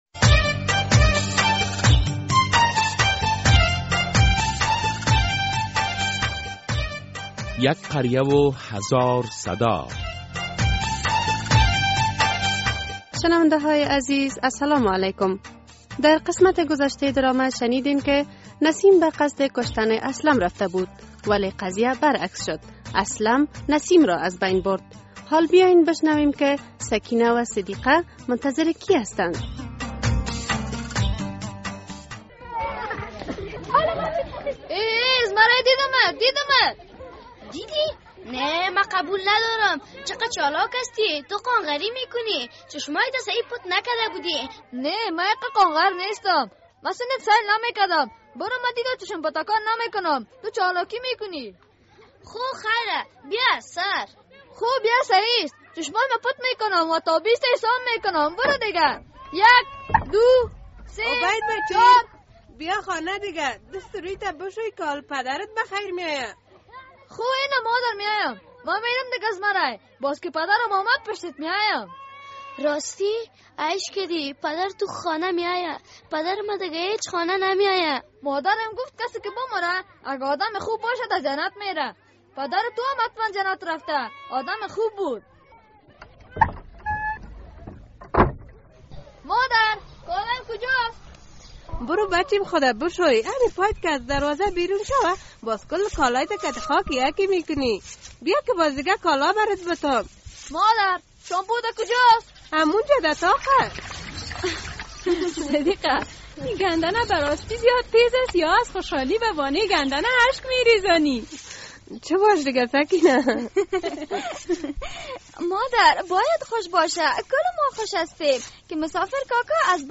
در این درامه که موضوعات مختلف مدنی، دینی، اخلاقی، اجتماعی و حقوقی بیان می گردد هر هفته به روز های دوشنبه ساعت 3:30 عصر از رادیو آزادی نشر می گردد.